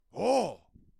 描述：感叹词"Roghr"。为一些短片录制的。
声道立体声